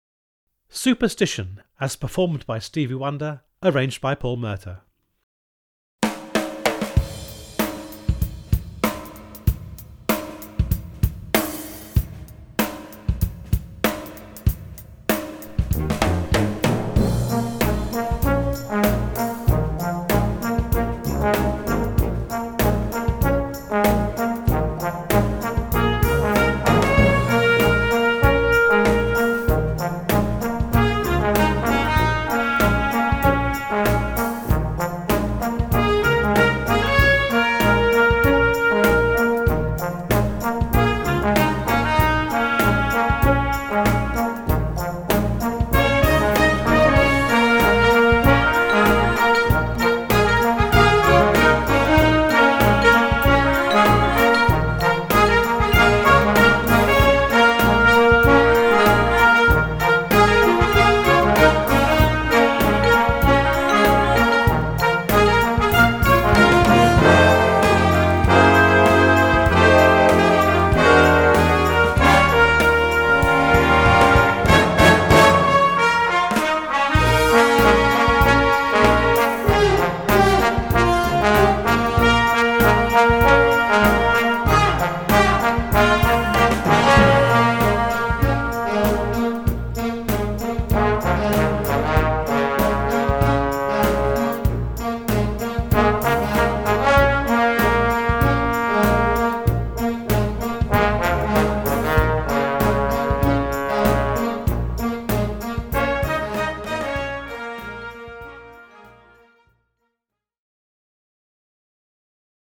features a funky groove and all the signature riffs.